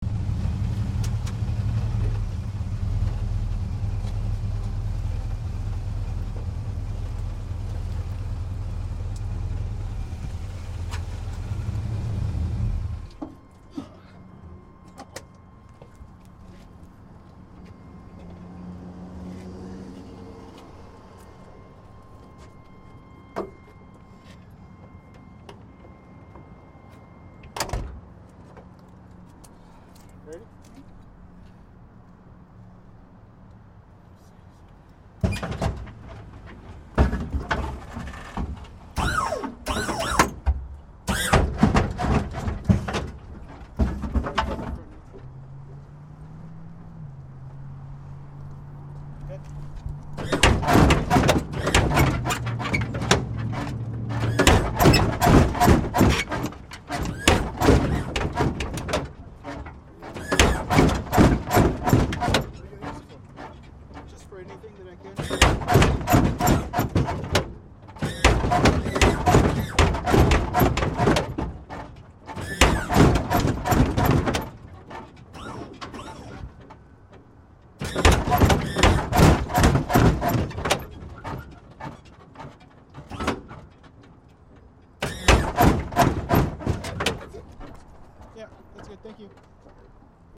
Download Hydraulic sound effect for free.
Hydraulic